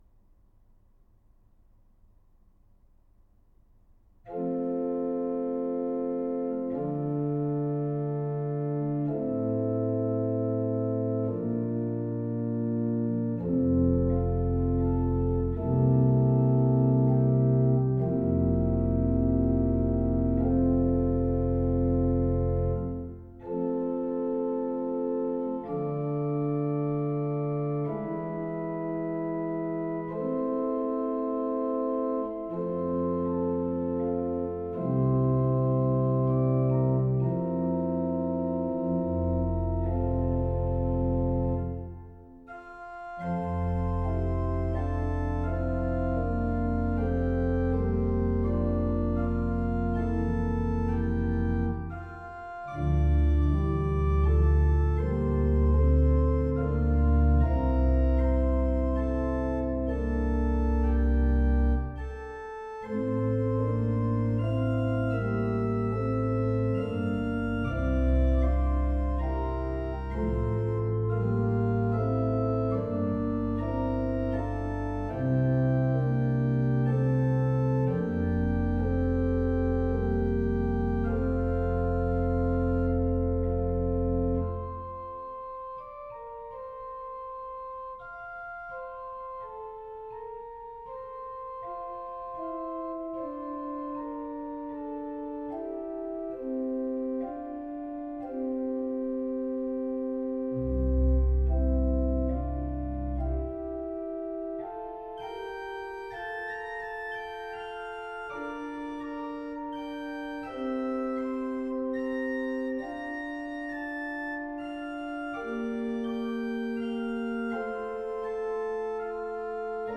organ Download PDF Duration